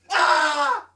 短暂的人声样本 " 清一色的男性喊话："你准备好了吗?！quot。
标签： 声音 大叫 呼喊 声音 清洁
声道立体声